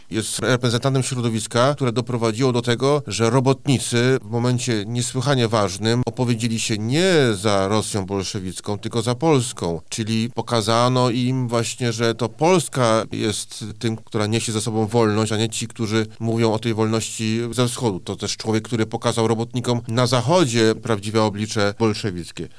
O tym, z czego szczególnie zasłynął nasz bohater, mówi zastępca prezesa IPN dr Mateusz Szpytma: